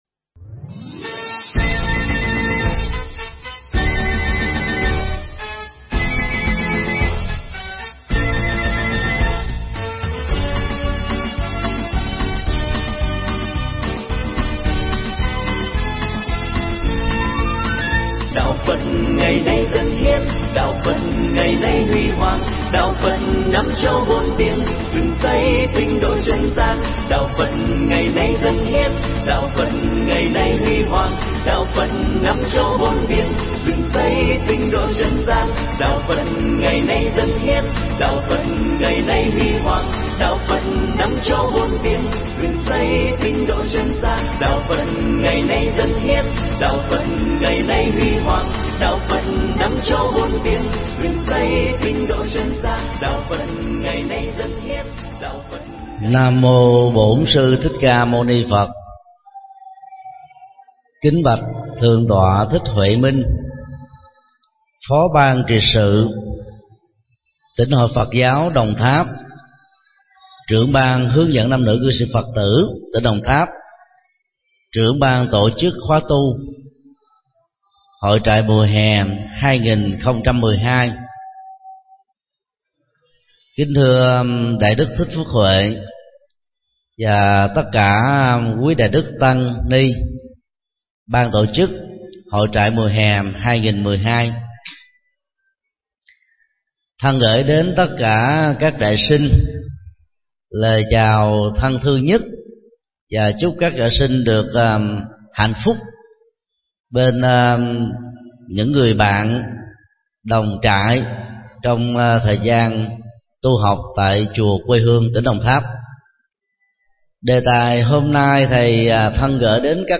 Mp3 Bài giảng Bảo bối trong đời do thầy Thích Nhật Từ giảng tại hội trại Tuổi Trẻ và Cuộc Sống lần thứ V tại chùa Quê Hương, Đồng Tháp, ngày 23 tháng 02